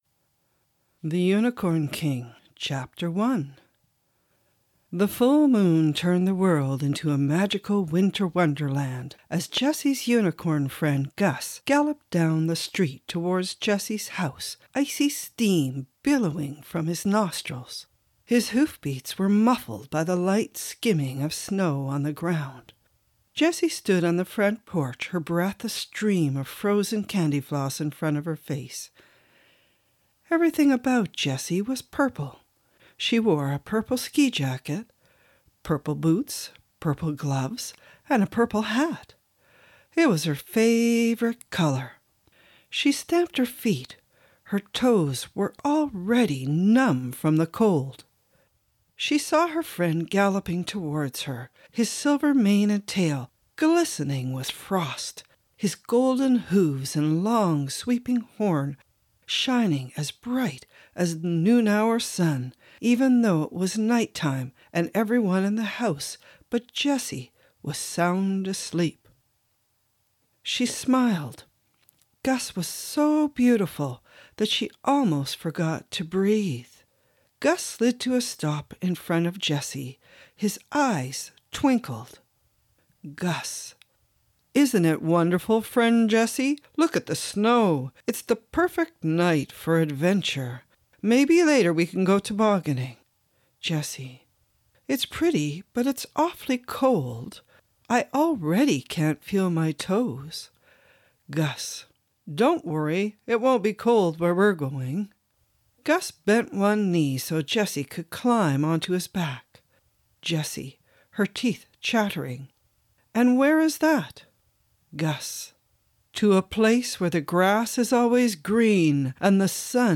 This is what started me reading these stories in the play format so that the younger and older children as well as those with learning disabilities could follow along.